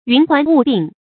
云鬟霧鬢 注音： ㄧㄨㄣˊ ㄏㄨㄢˊ ㄨˋ ㄅㄧㄣˋ 讀音讀法： 意思解釋： 頭發象飄浮縈繞的云霧。形容女子發美。